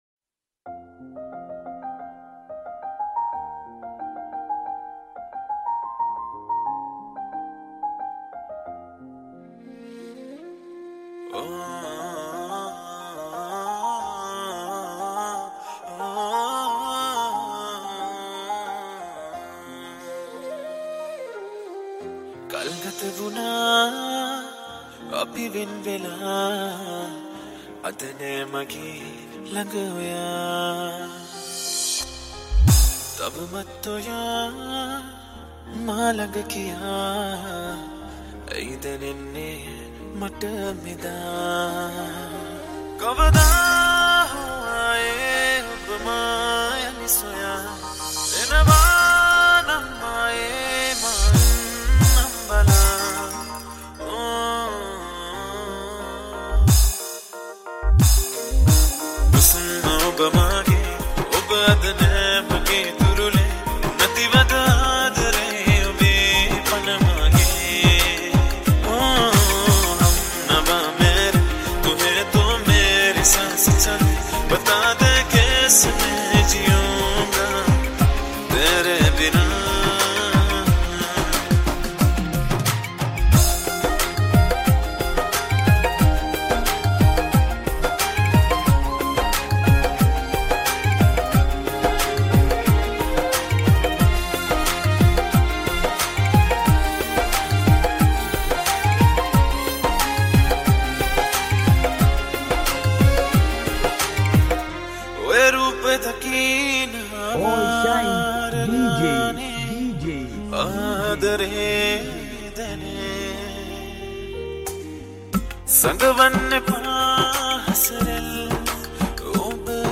Romantic Dj Remix